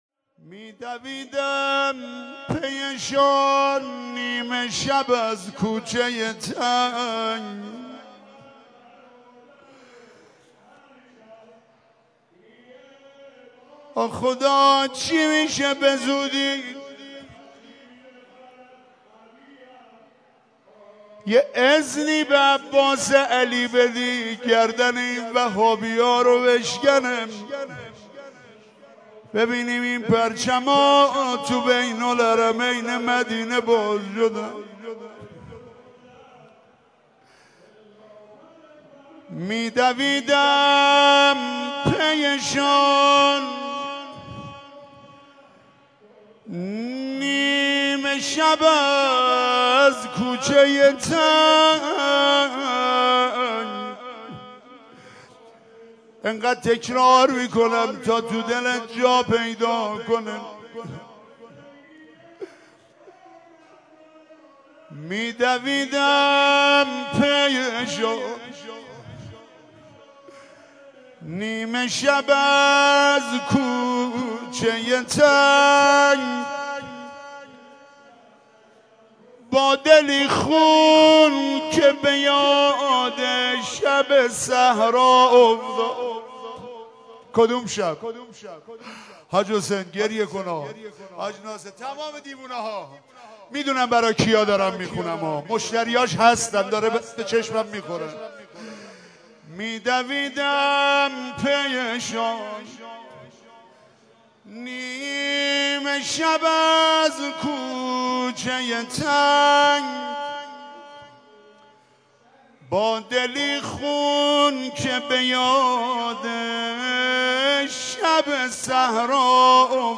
عزاداری در بازار تهران